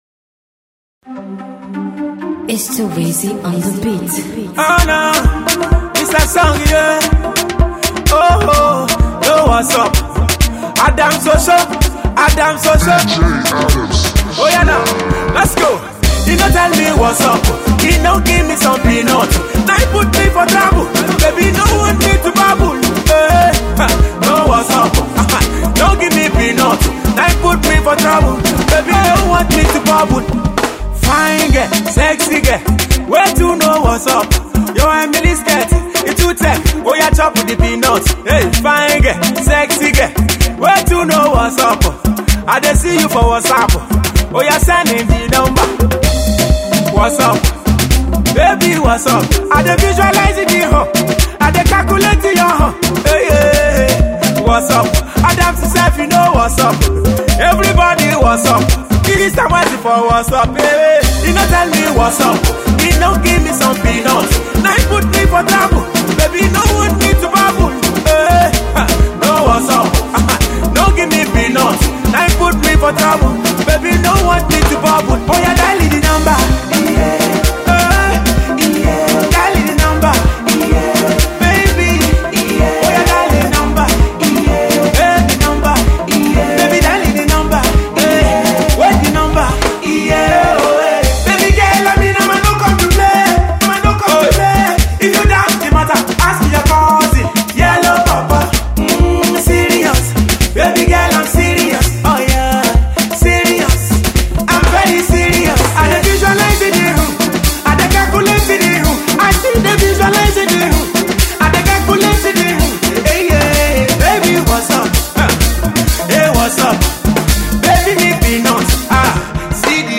a party tune with lovely melody and rhythm.
is a very catchy and highly addictive